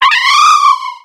Cri de Lakmécygne dans Pokémon X et Y.